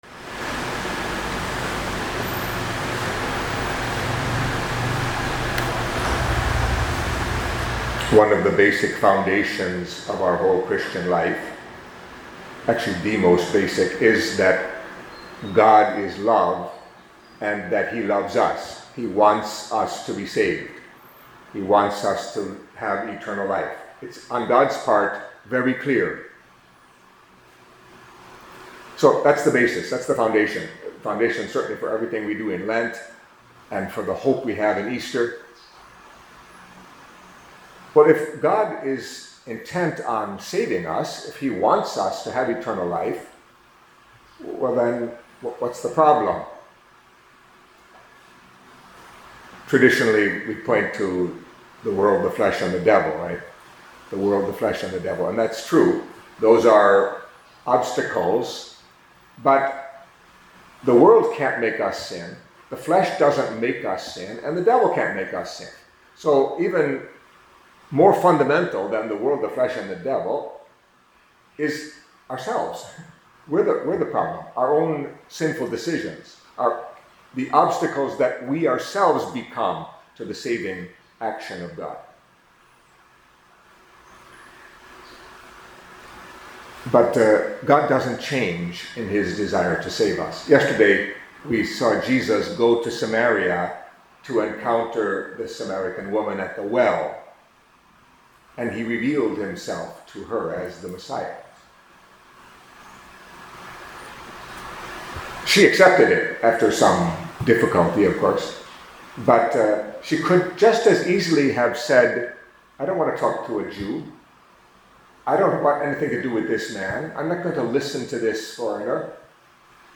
Catholic Mass homily for Monday of the Third Week of Lent